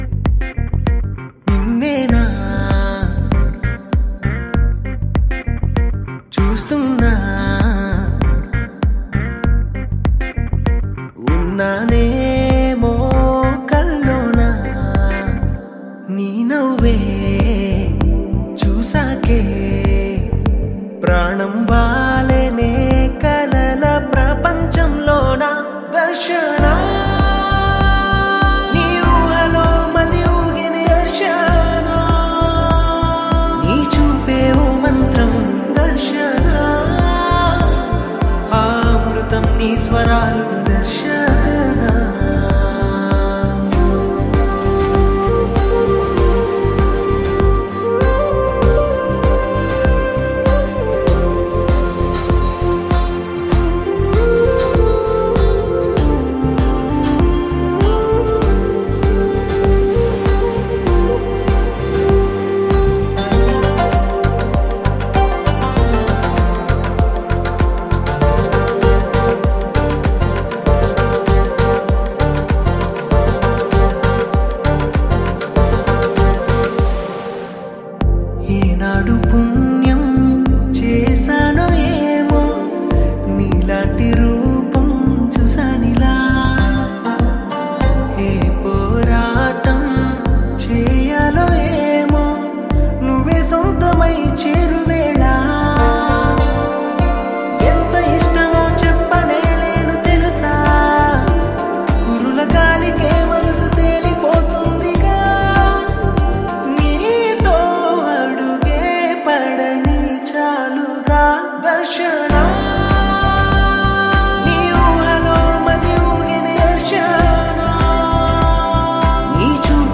is a gentle Telugu melody
with his soft and emotional voice